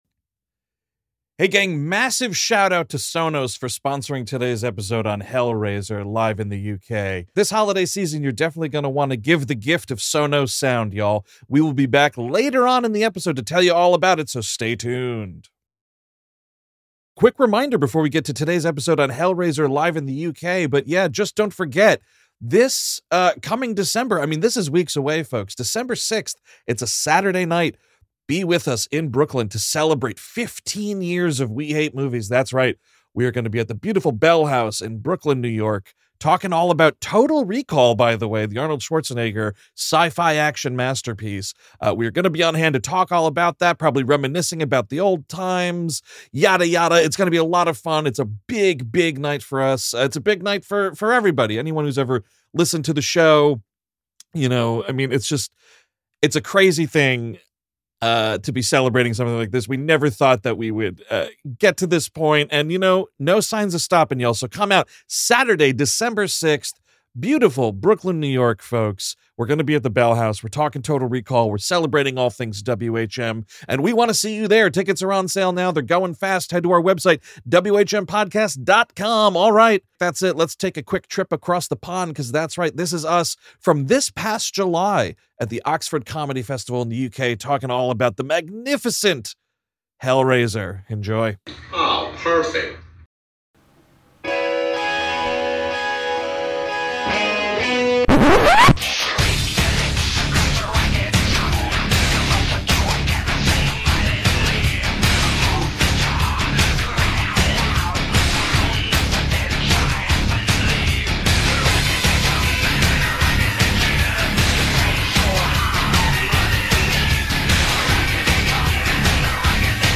Hellraiser '87 (Live in the U.K., W❤M)
On this week’s episode, it’s our outrageous show from the U.K. where, on night two of our residency, we performed this wild We ❤ Movies set on the fantastic horror classic, Hellraiser!